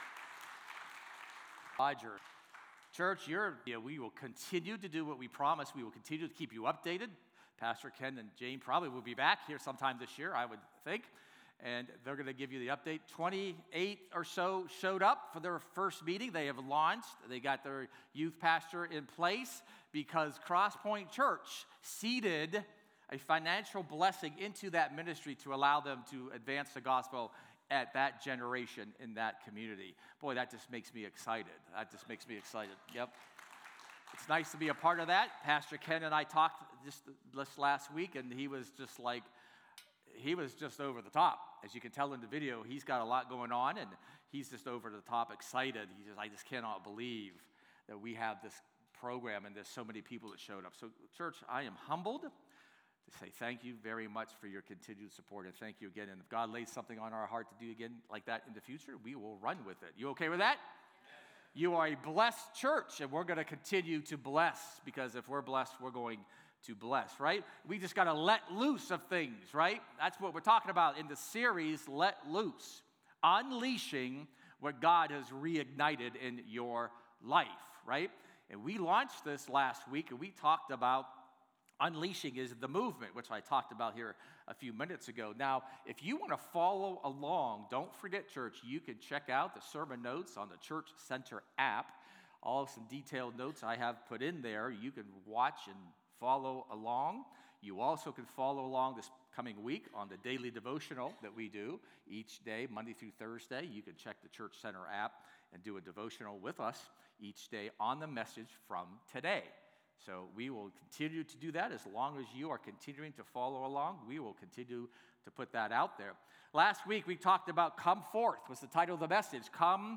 “Let Loose” is a bold, Spirit-led sermon series that calls believers to move beyond revival and into release.